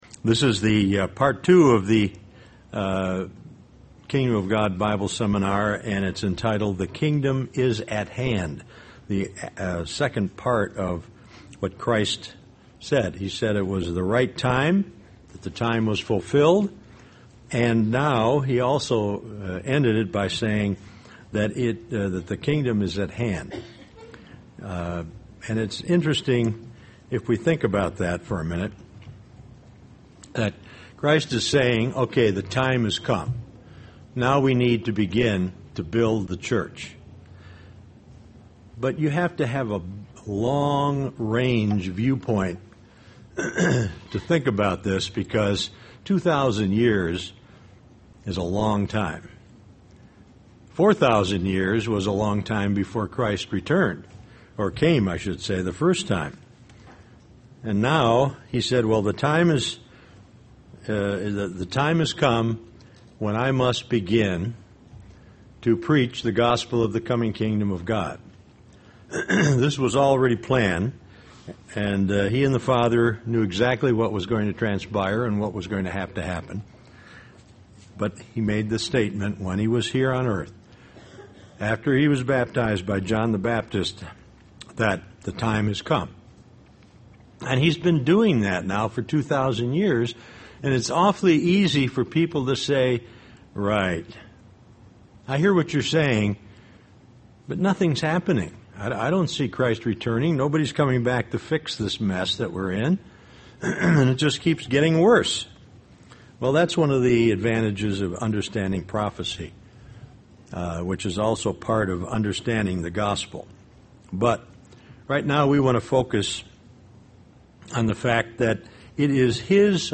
This message was given as a Kingdom of God Bible seminar.